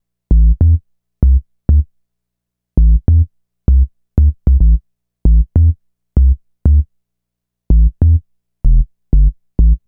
Bass 26.wav